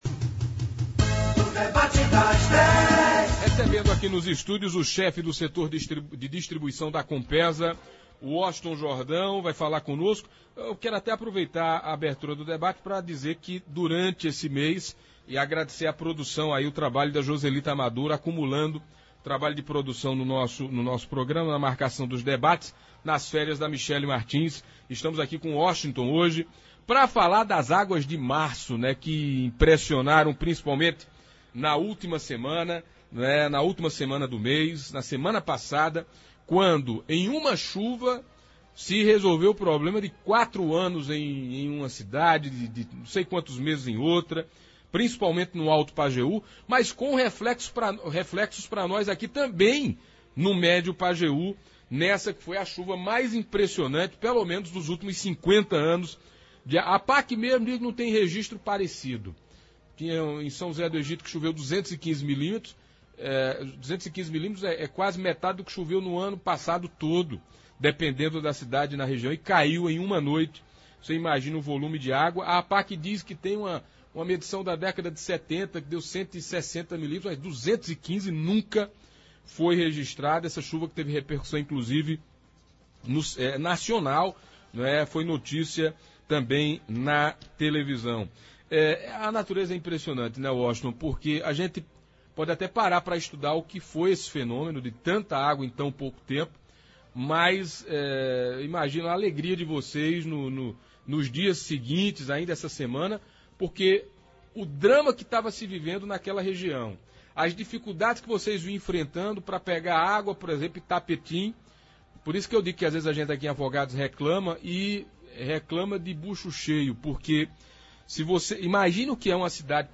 respondeu a questionamentos dos ouvintes e internautas